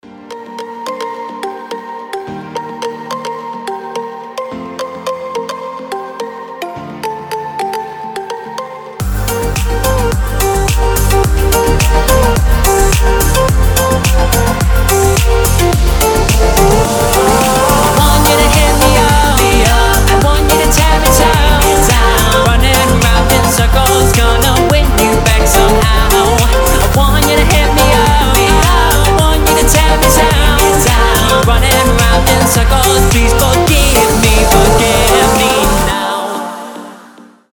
• Качество: 320, Stereo
deep house
красивая мелодия
нарастающие
tropical house
Chill
Стиль: tropical house, chill, deep house